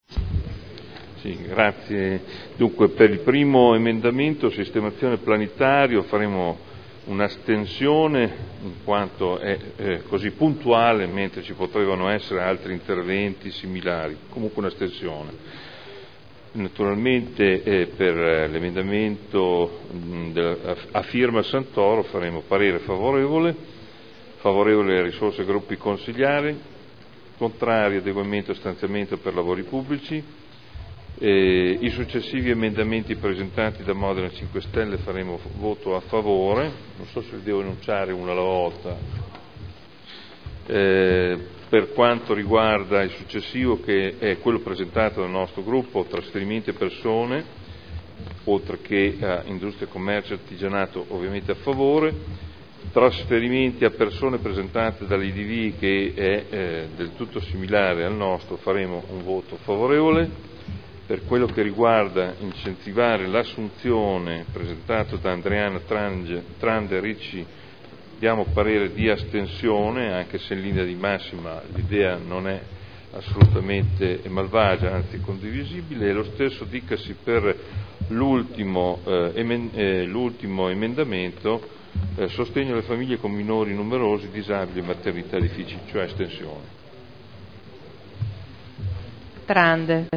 Seduta del 28/03/2011. Dichiarazioni di voto su emendamenti.